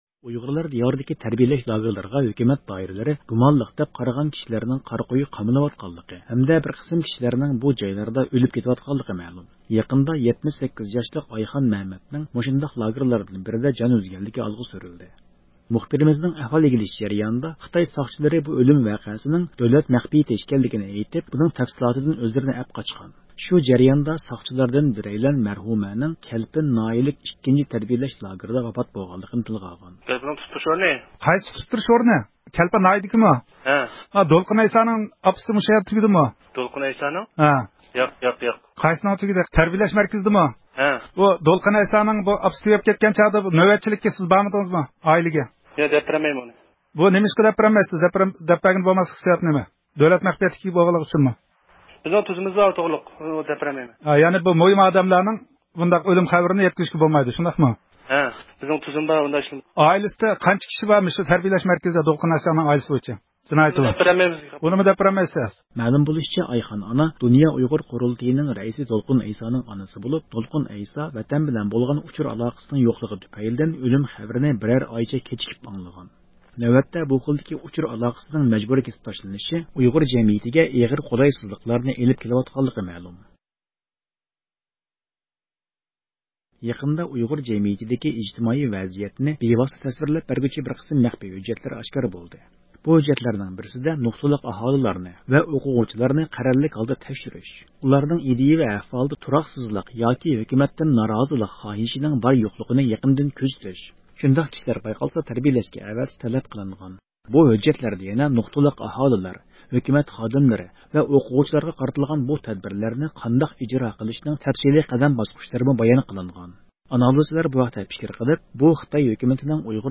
ھەپتىلىك خەۋەرلەر (30-ئىيۇندىن 6-ئىيۇلغىچە) – ئۇيغۇر مىللى ھەركىتى